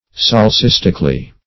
Solecistically \Sol`e*cis"tic*al*ly\, adv.
solecistically.mp3